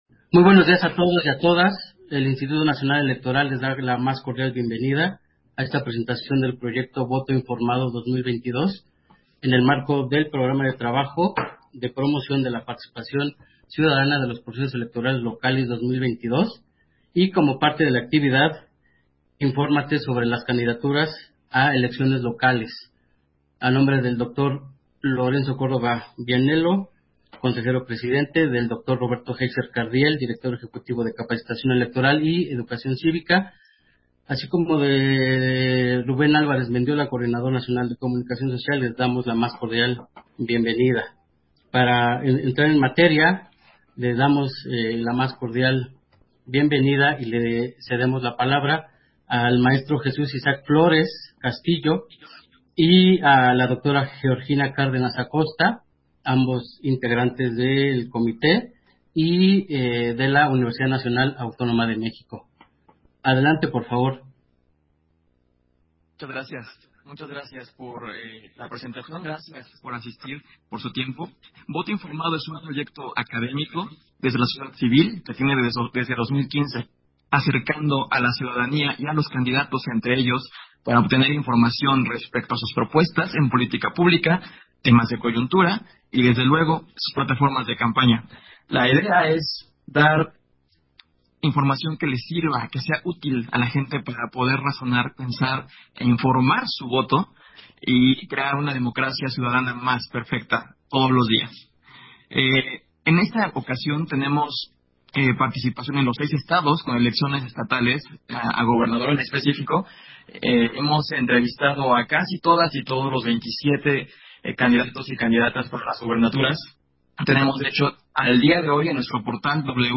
160522_AUDIO_CONFERENCIA-DE-PRENSA-PROYECTO-VOTO-INFORMADO - Central Electoral